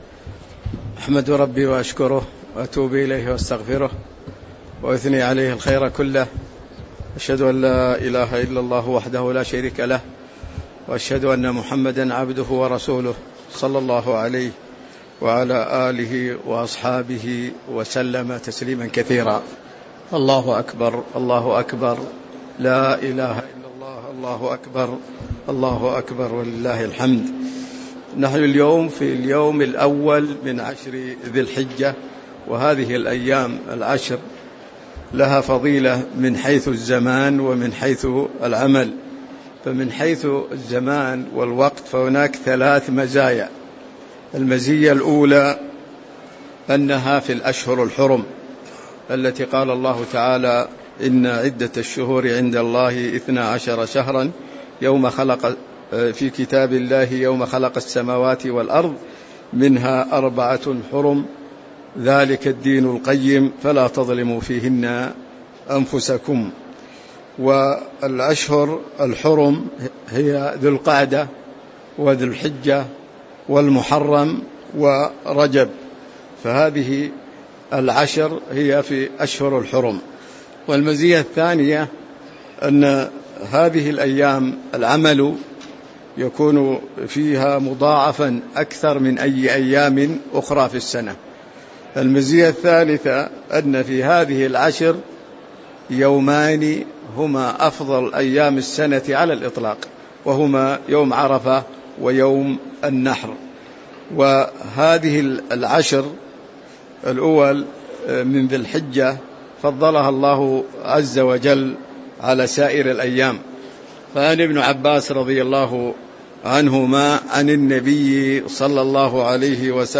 تاريخ النشر ١ ذو الحجة ١٤٤٤ هـ المكان: المسجد النبوي الشيخ